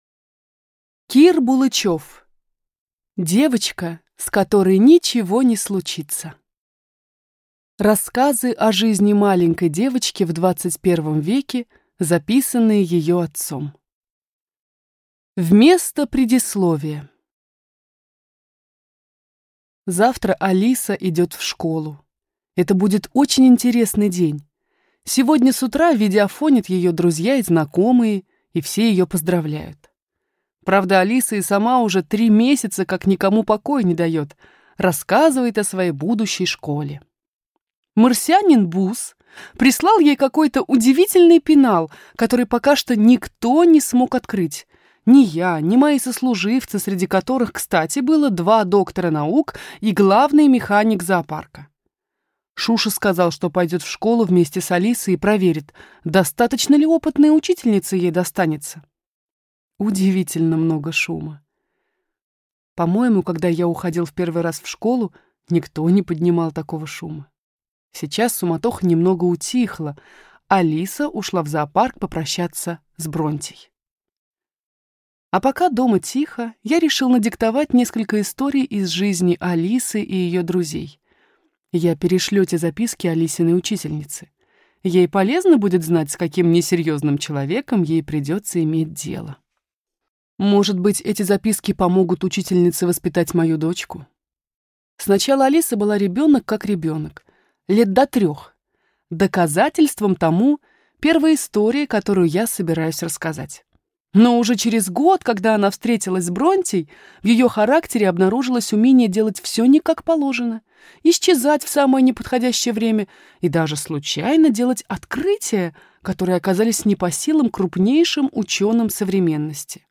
Аудиокнига Девочка, с которой ничего не случится. Ржавый фельдмаршал | Библиотека аудиокниг